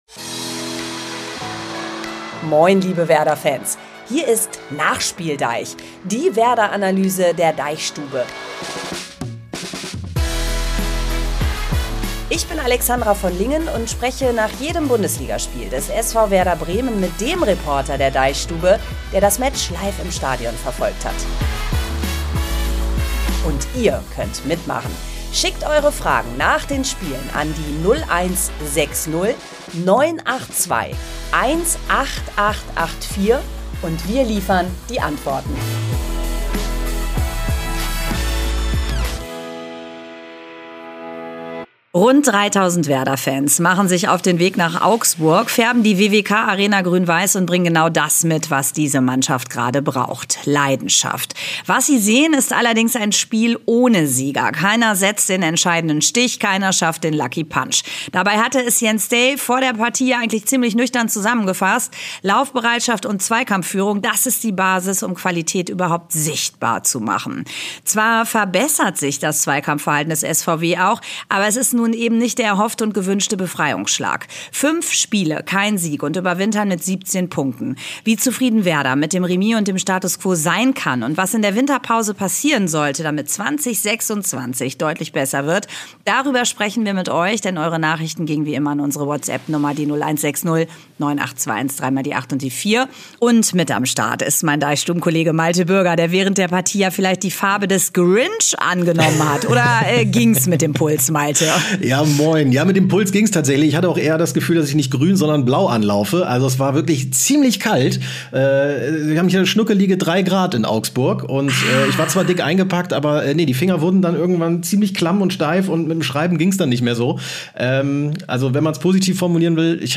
NachspielDEICH ist ein Fußball-Podcast der DeichStube.